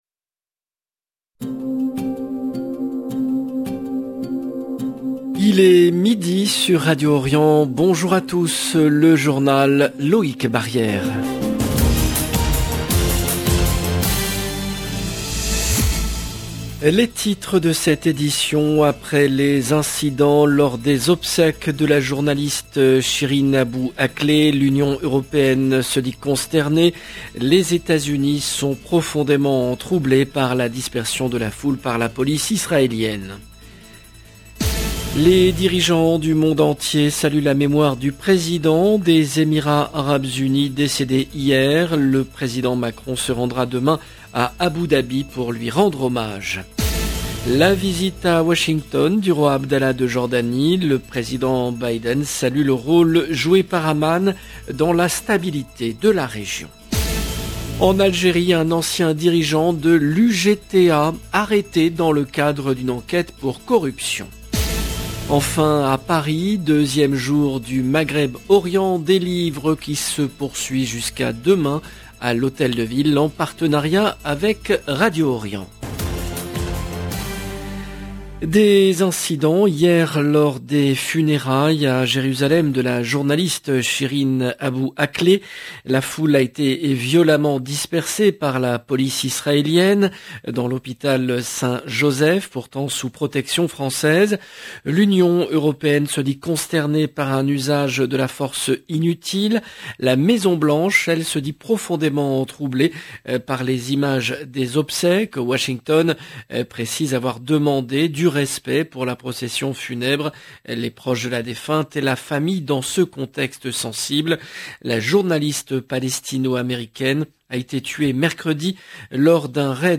LE JOURNAL EN LANGUE FRANCAISE DE MIDI DU 14/05/22